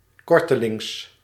Ääntäminen
IPA : /ˈɹiː.sənt/ US : IPA : [ˈɹiː.sənt]